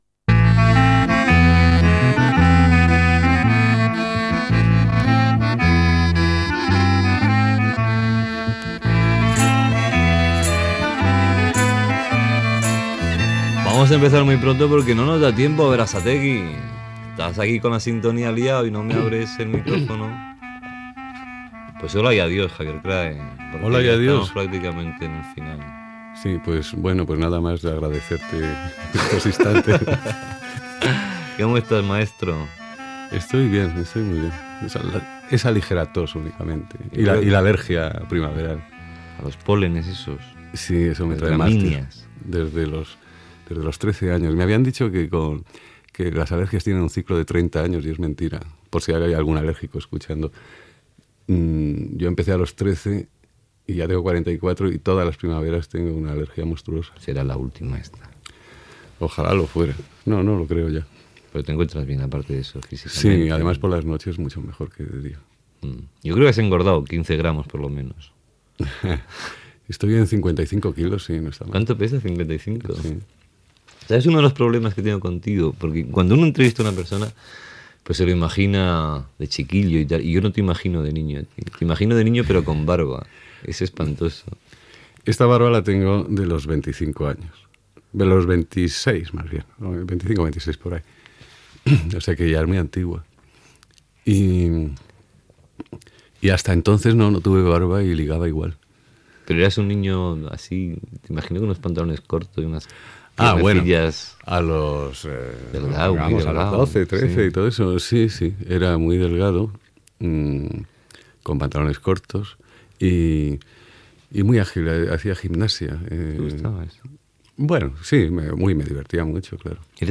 Entrevista al cantant Javier Krahe